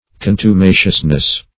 Con`tu*ma"cious*ness, n.